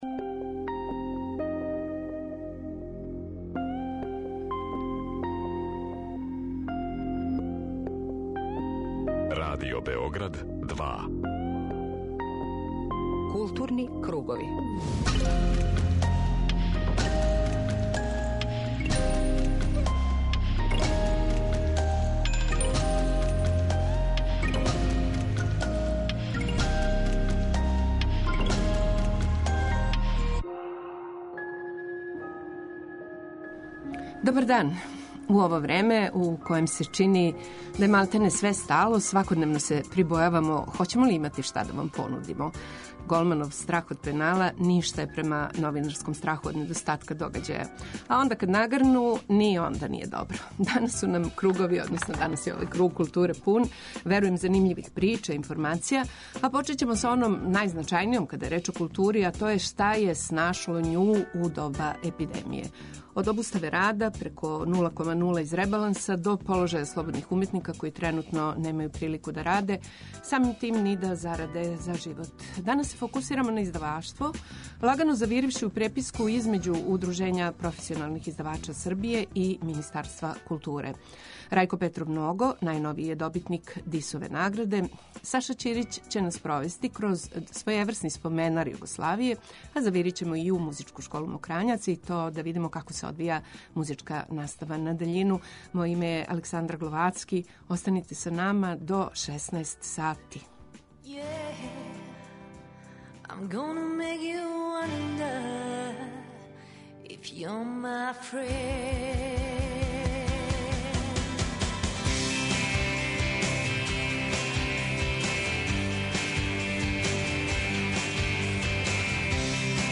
За емисију Културни кругови говори песник Рајко Петров Ного, овогодишњи лауреат Дисове награде коју додељује Градска библиотека из Чачка и Одбор 57. Дисовог пролећа.
преузми : 19.66 MB Културни кругови Autor: Група аутора Централна културно-уметничка емисија Радио Београда 2.